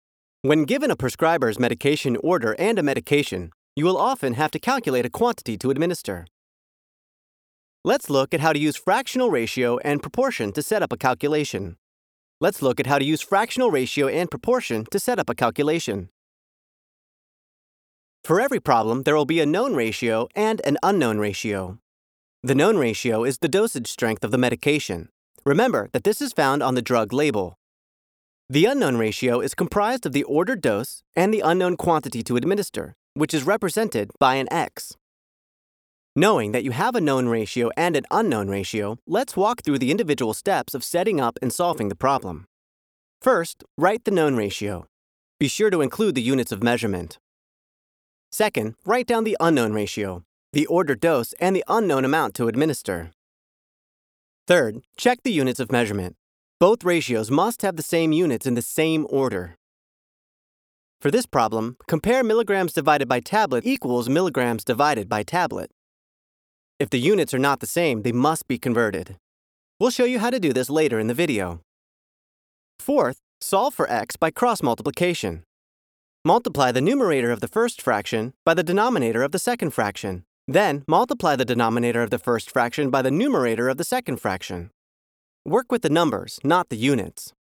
eLearning Demo
Young Adult
Middle Aged